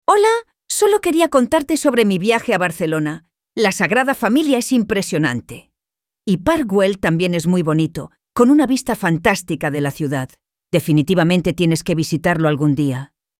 spanish_speech.mp3